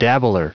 Prononciation du mot dabbler en anglais (fichier audio)
Prononciation du mot : dabbler